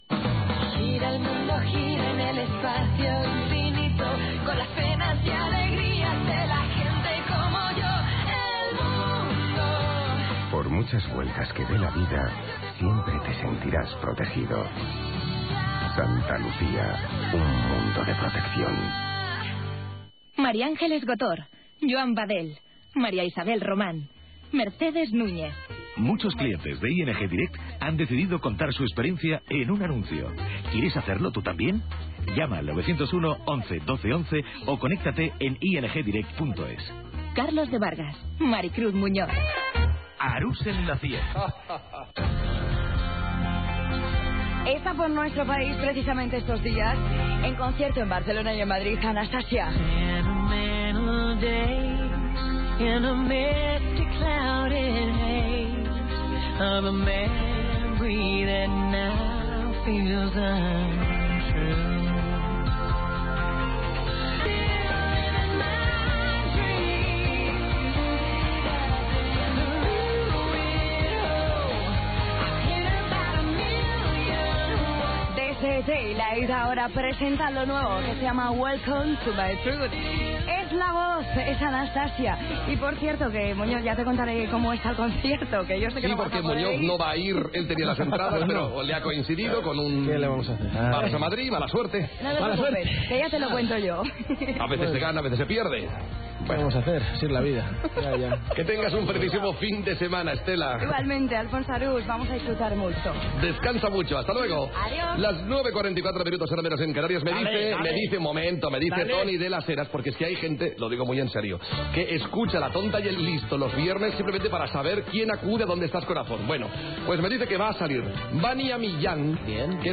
Publicitat, indicatiu del programa, tema musical
Indicatius del programa i de la cadena Gènere radiofònic Entreteniment